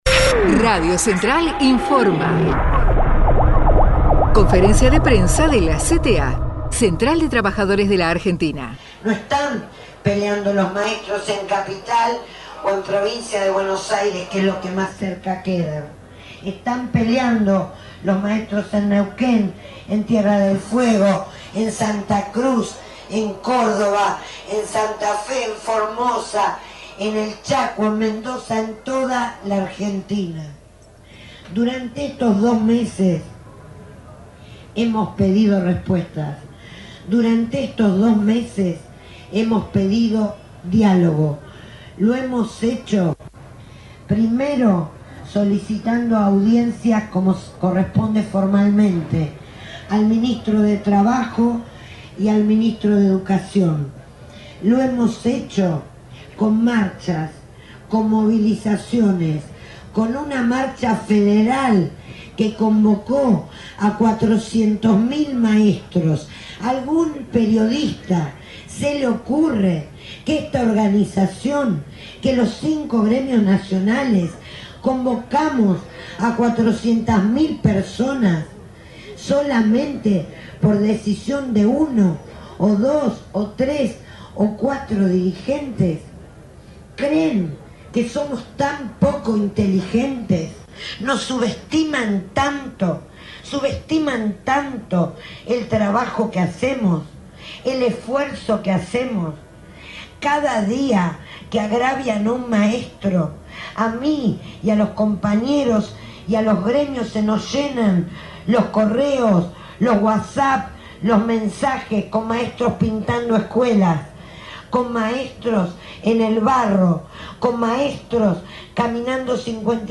Inauguración Escuela Pública Itinerante